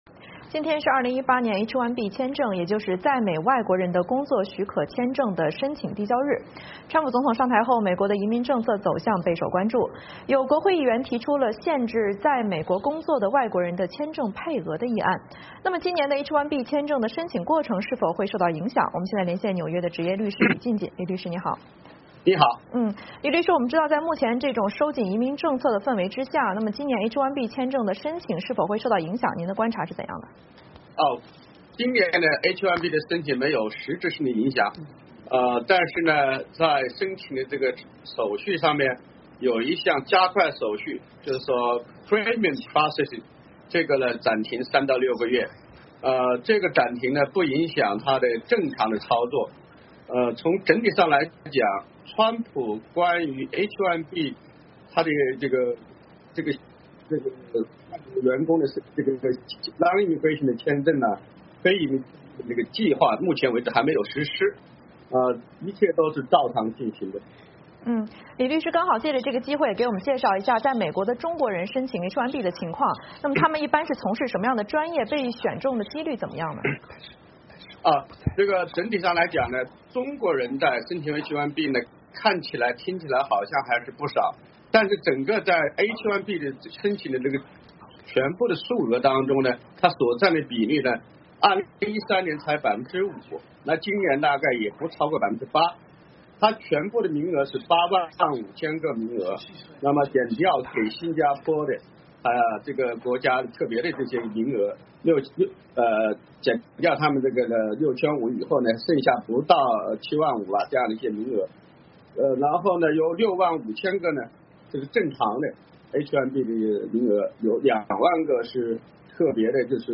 VOA连线: 川普移民政策是否影响今年H1B申请？